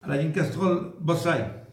Saint-Jean-de-Monts
Catégorie Locution